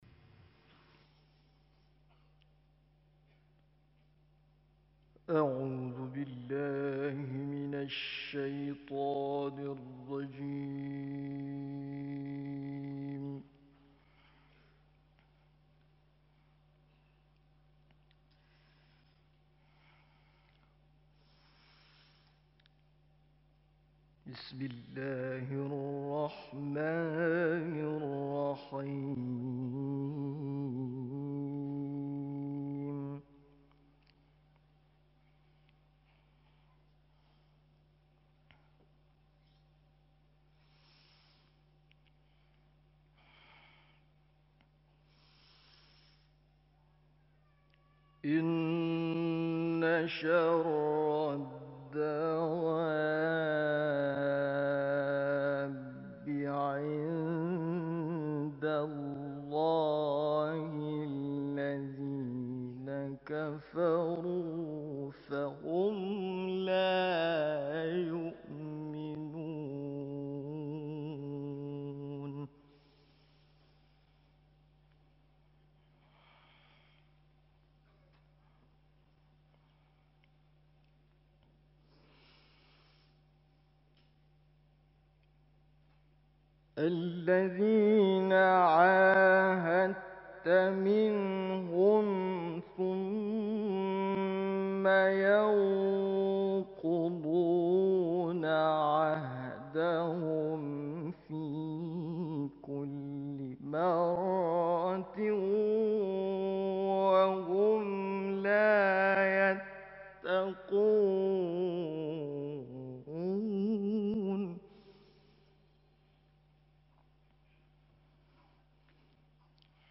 تحلیل تلاوت‌های روز دوم مسابقات قرآن مالزی + صوت